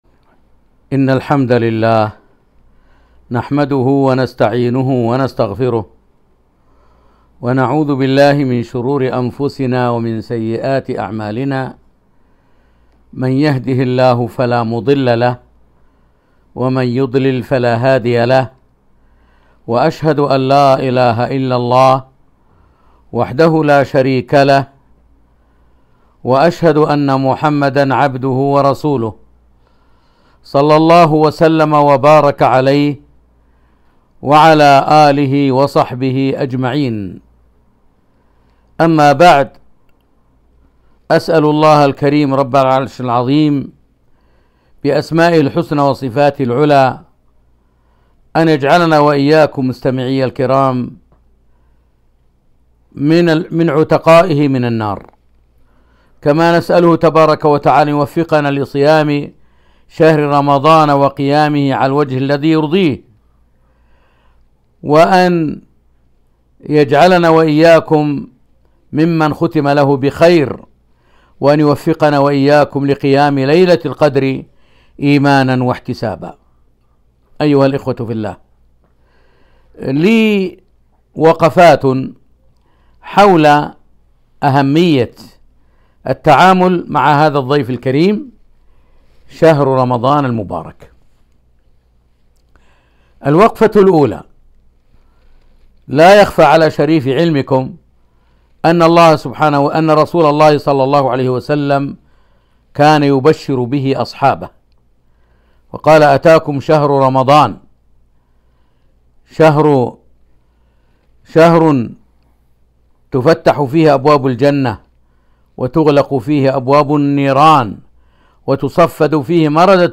كلمة - وقفات مع بعض أحكام شهر الصوم